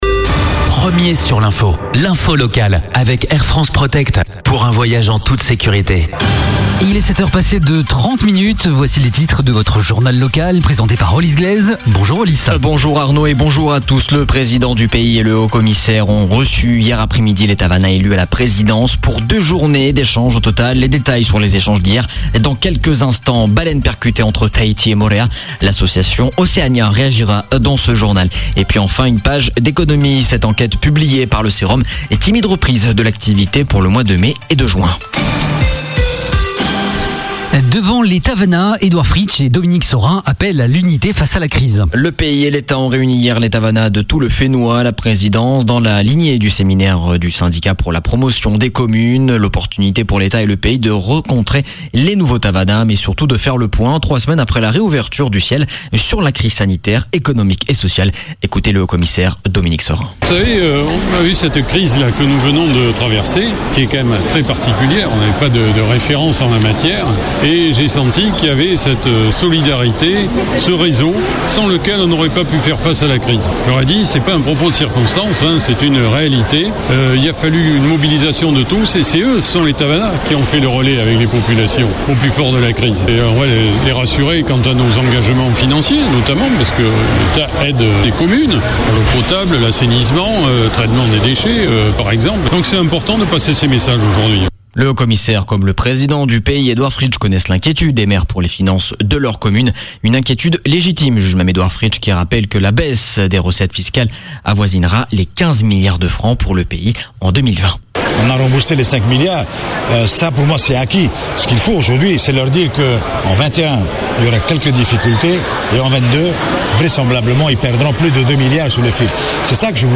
Journal de 7:30, le 07/08/2020